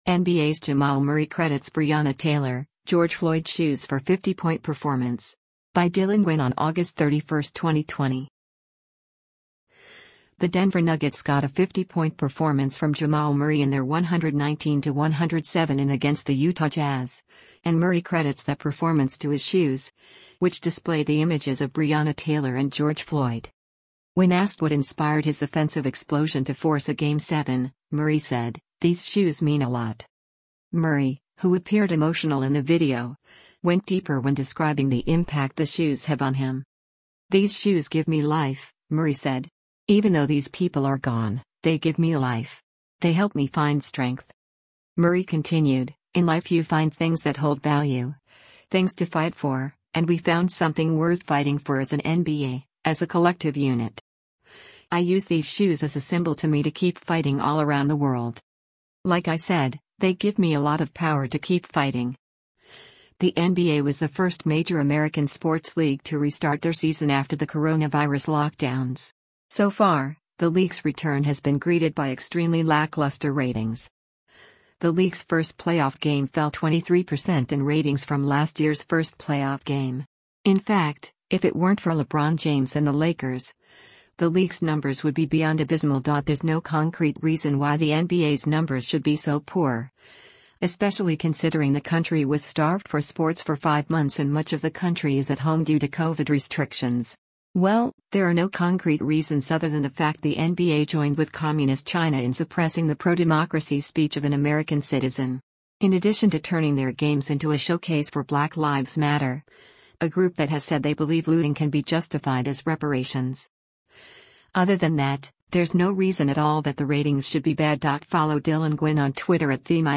Murray, who appeared emotional in the video, went deeper when describing the impact the shoes have on him.